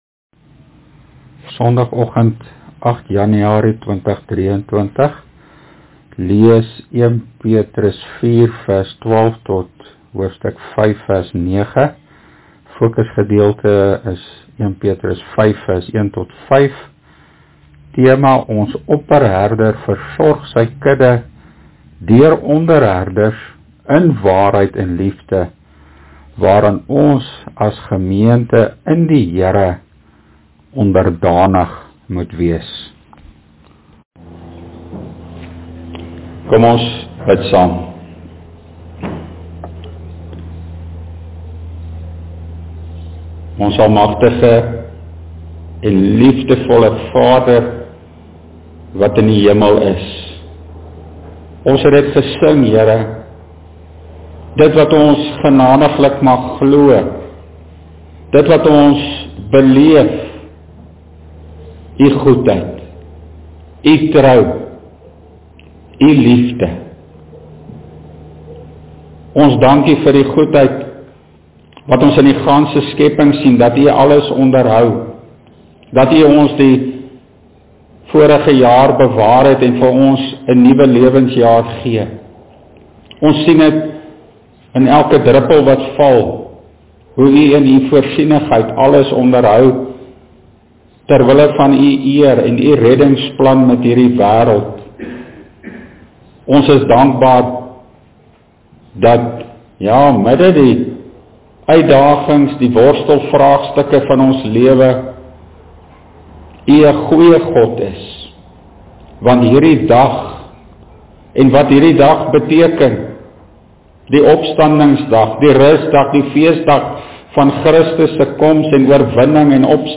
Preek: 1 Petrus 5:1-5
Preekopname: GK Carletonville, 2023-01-08: